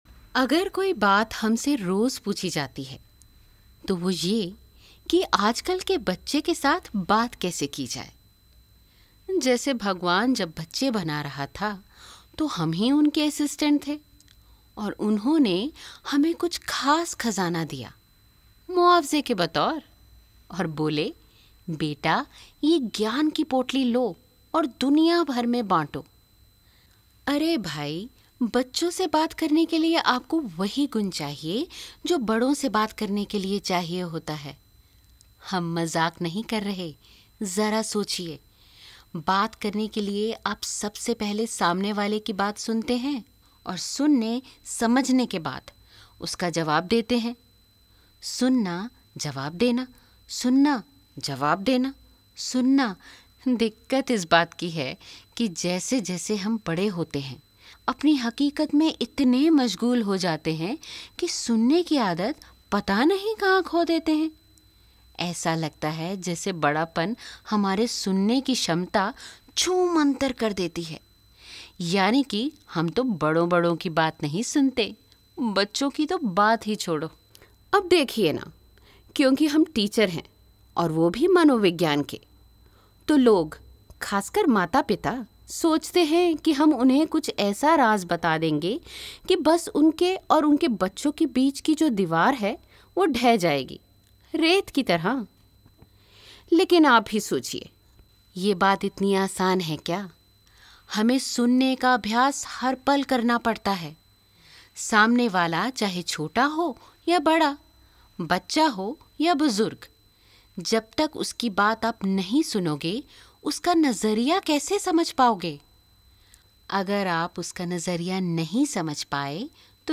Female
My voice is pleasant , soft and compassionate.
Natural Speak
Monologue